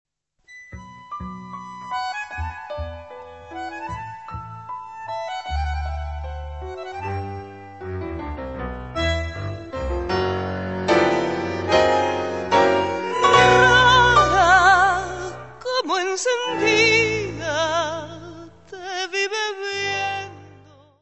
Bandoneón
piano
contrabaixo
Music Category/Genre:  World and Traditional Music